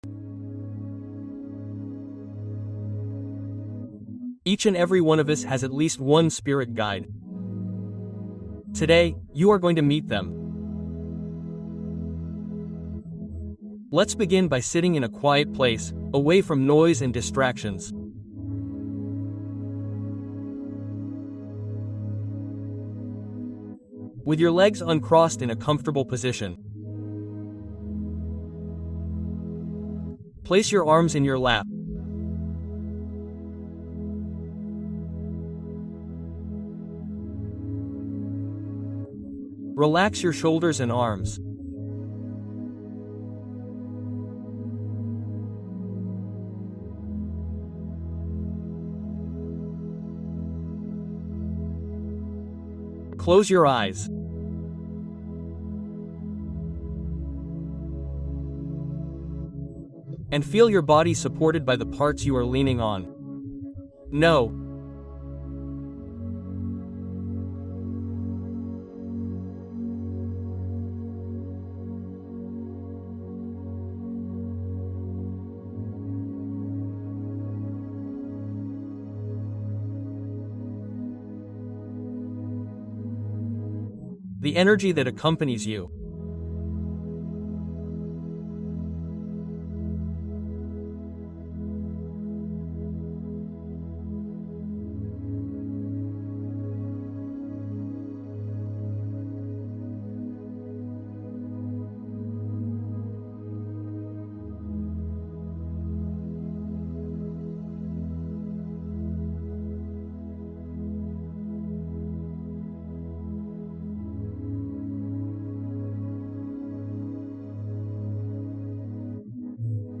Contacto Interno con Guías Espirituales: Meditación de Sensación Sutil